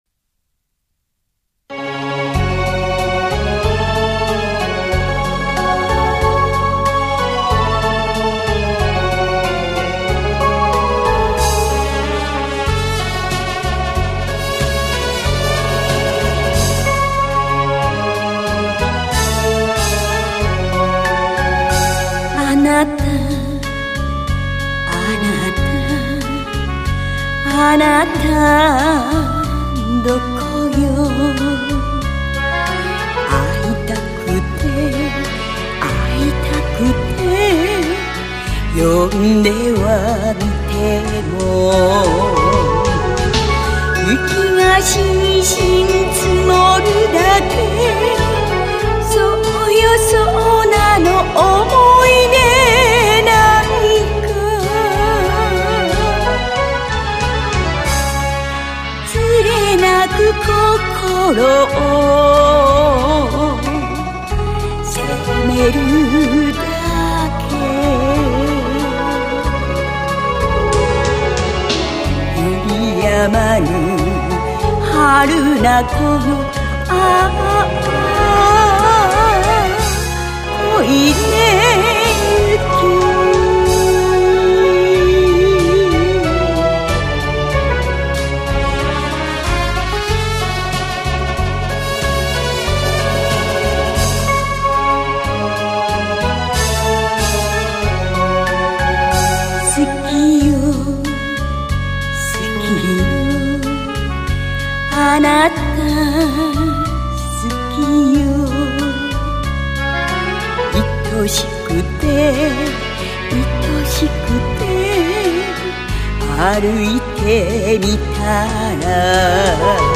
カラオケ